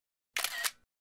Звуки вспышки фотоаппарата
Звук всплеска камеры в iPhone